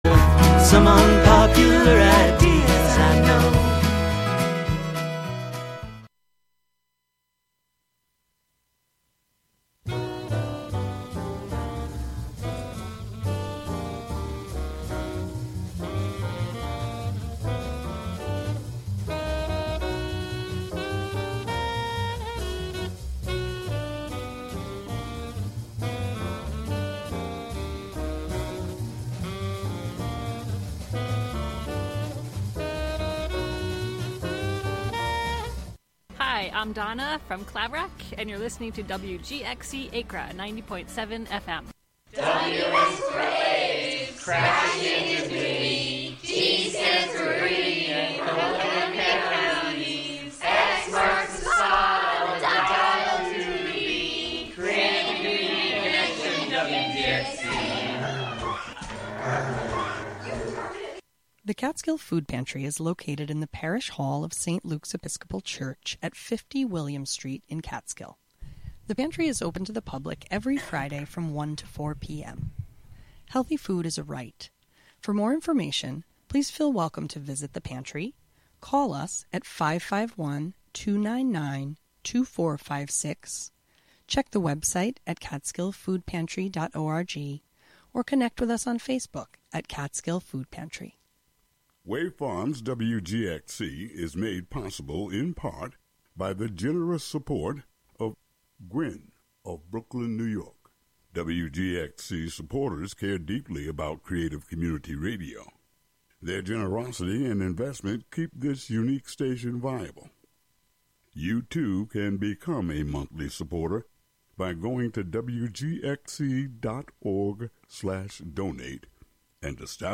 7pm "Foraging Ahead" features music and interviews fro...
broadcast live from WGXC's Catskill studio.